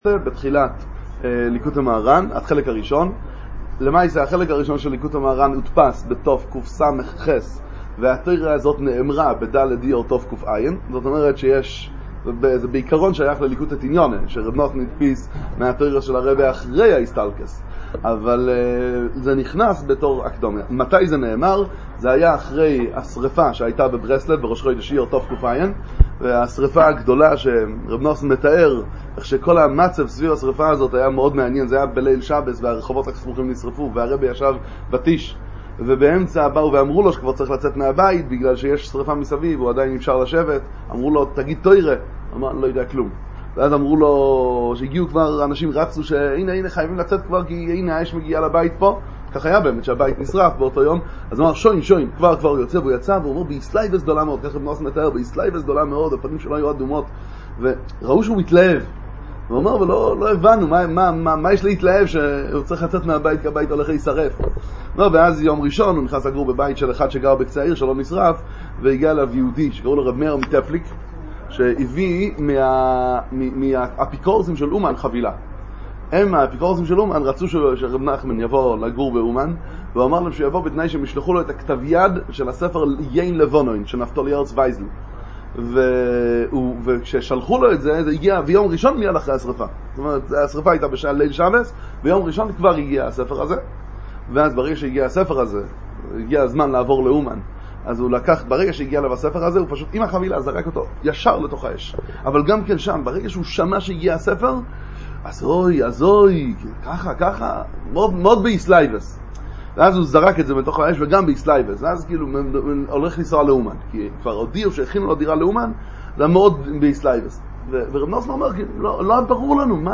דבר תורה ל"ג בעומר, שיעור על גדולת רשב"י, שיעורי תורה בענין רבי שמעון בר יוחאי בתורת ברסלב